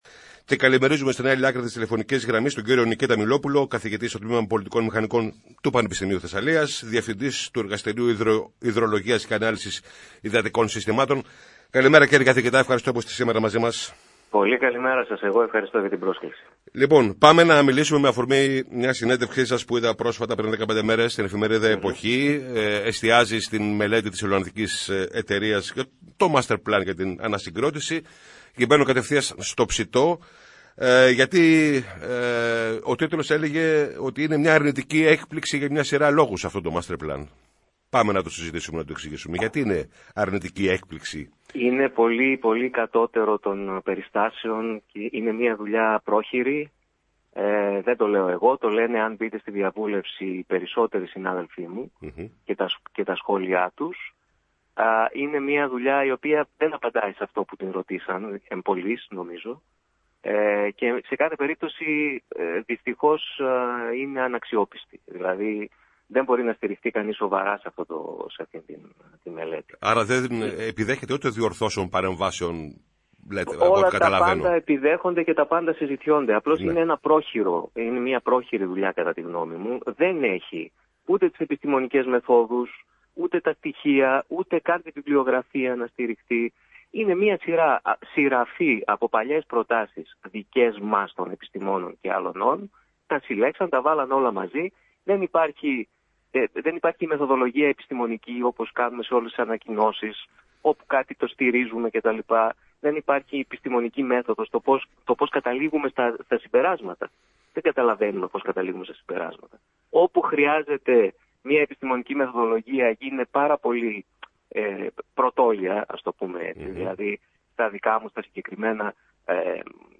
στο ραδιόφωνο της ΕΡΤ Λάρισας